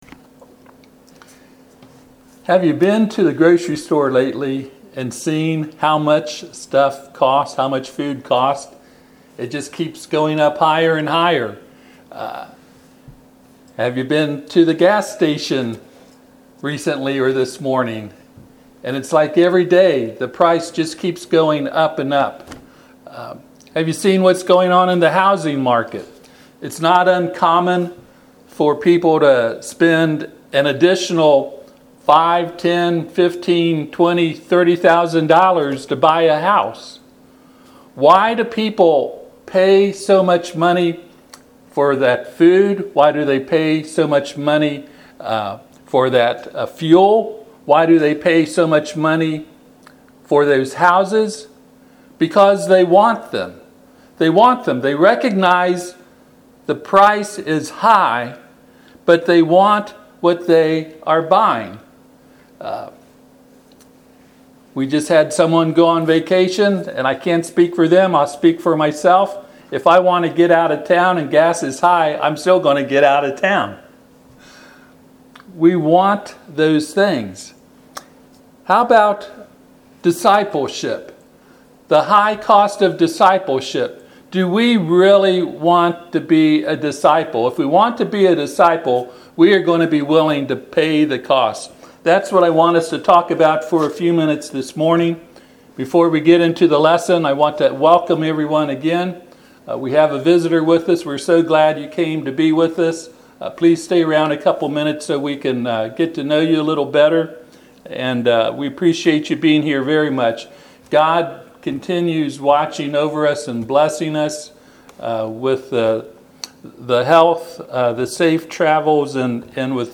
Passage: Luke 9:57-62 Service Type: Sunday AM